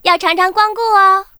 文件 文件历史 文件用途 全域文件用途 Daphne_fw_01.ogg （Ogg Vorbis声音文件，长度0.0秒，0 bps，文件大小：20 KB） 源地址:游戏语音 文件历史 点击某个日期/时间查看对应时刻的文件。